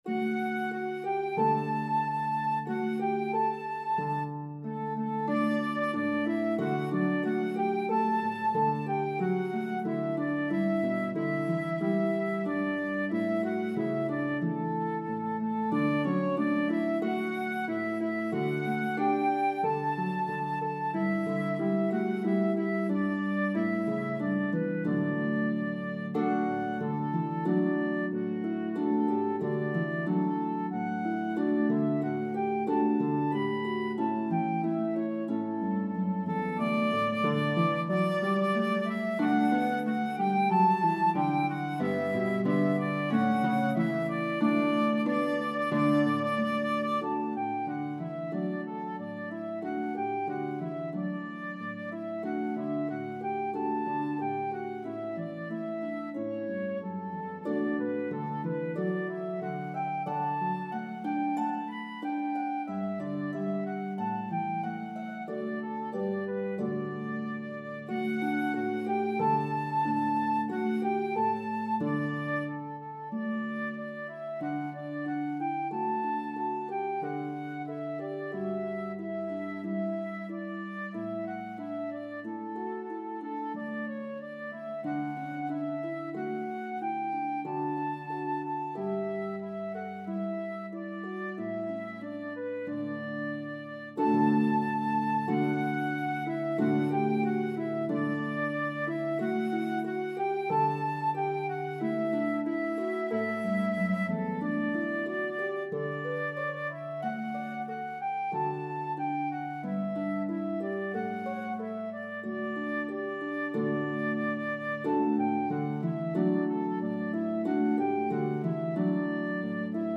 Shaker “quick dance”